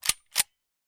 Звуки пистолета
Пистолет - Услышьте это